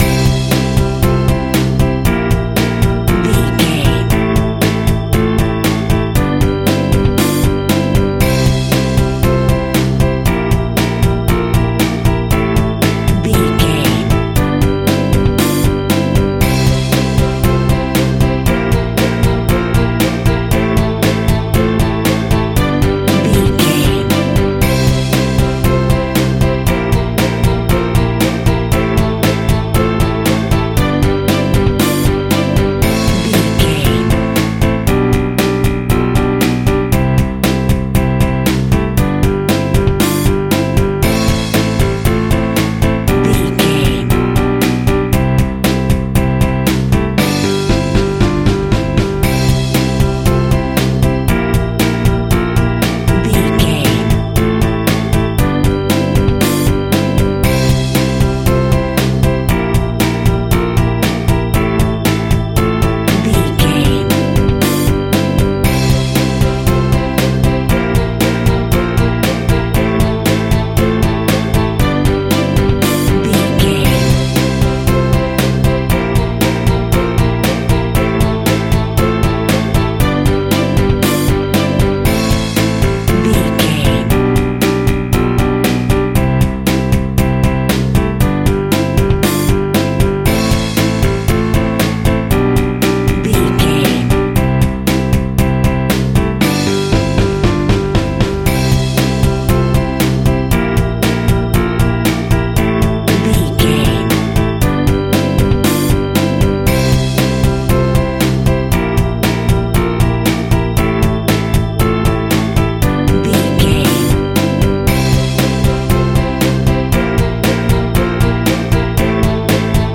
Ionian/Major
pop rock
indie pop
fun
energetic
uplifting
drums
bass guitar
piano
hammond organ
synth